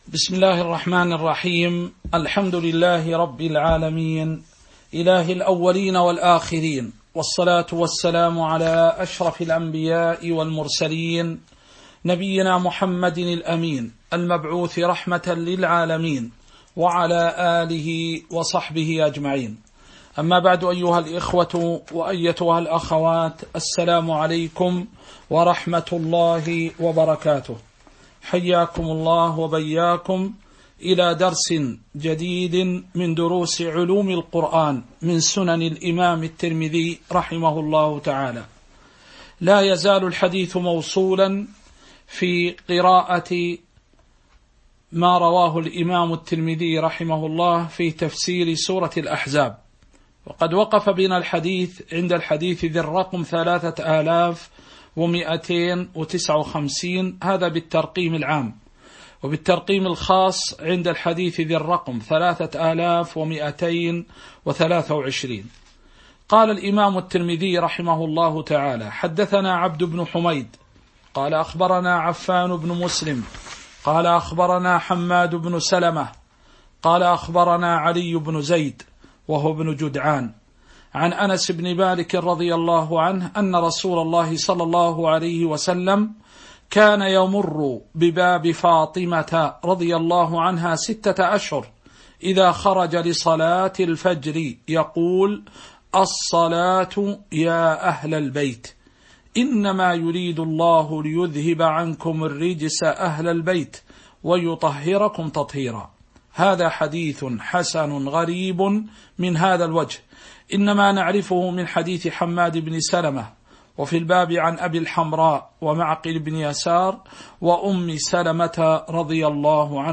تاريخ النشر ١٩ جمادى الآخرة ١٤٤٣ هـ المكان: المسجد النبوي الشيخ